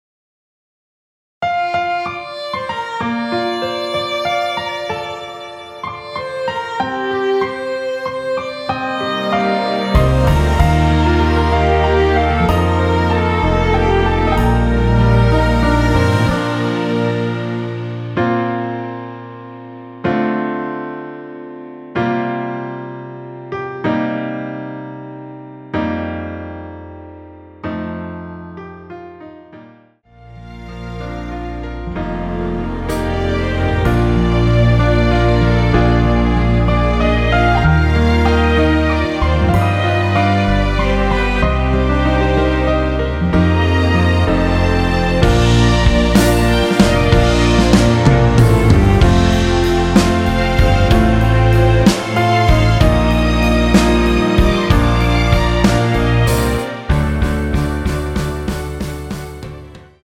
원키에서(-1)내린 1절후 후렴으로 진행되는 MR입니다.
Bb